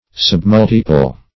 submultiple - definition of submultiple - synonyms, pronunciation, spelling from Free Dictionary
Submultiple \Sub*mul"ti*ple\, n. (Math.)